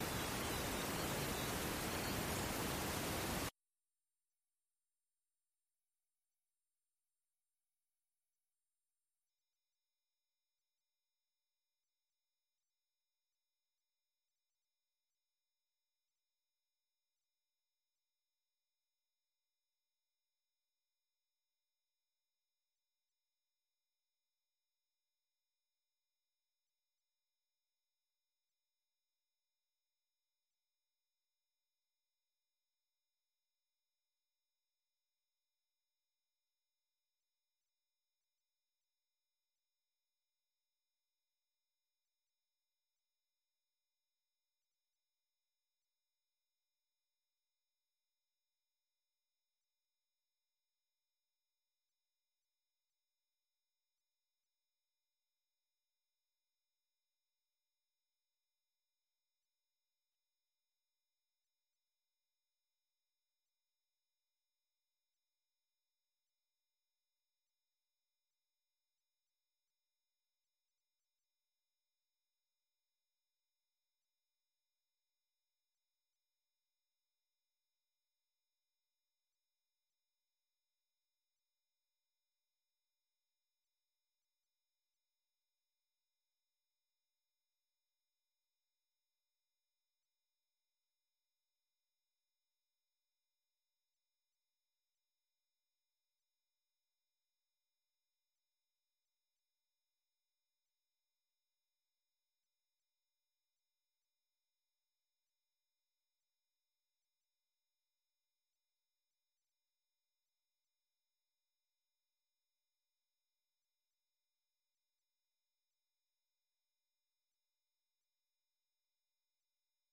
ข่าวสดสายตรงจากวีโอเอ ไทย อังคาร 28 มิ.ย.2565